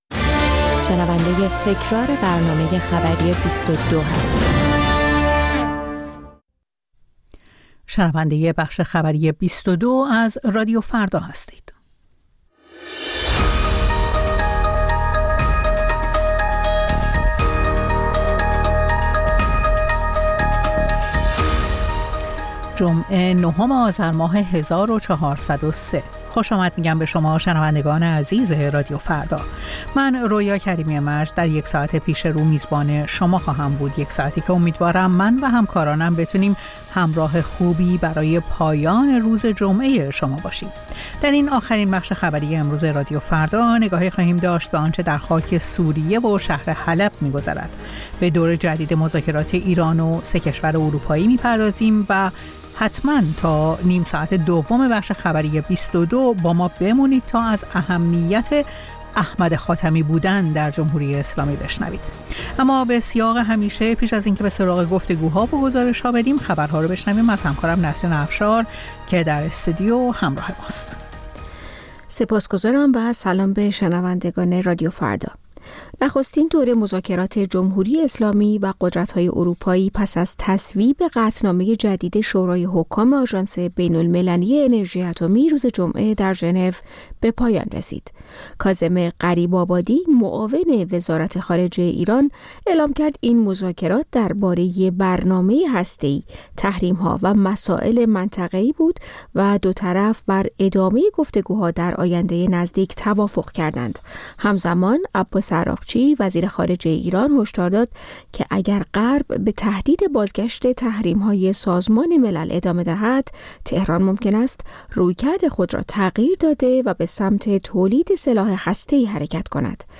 بازپخش برنامه خبری ۲۲